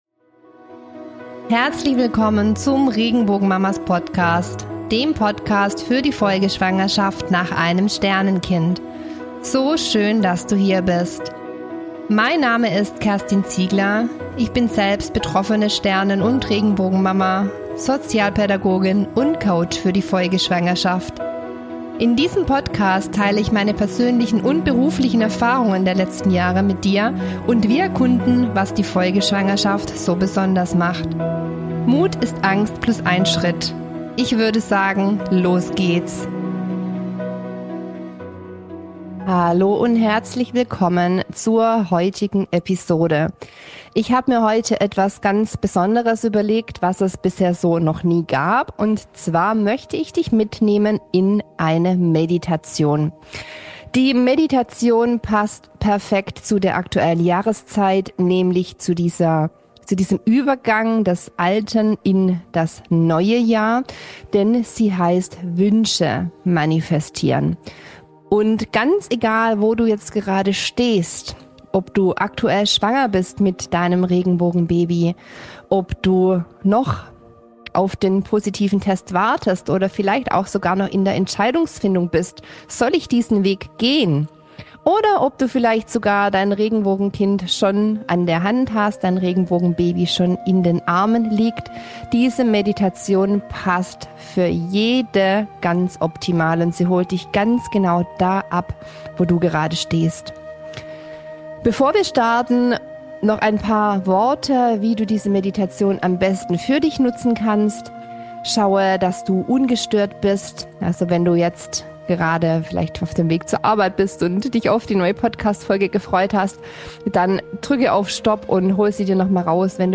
Folge 031 - Meditation "Wünsche manifestieren" ~ Regenbogenmamas - Der Podcast für deine Folgeschwangerschaft Podcast